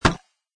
icemetal2.mp3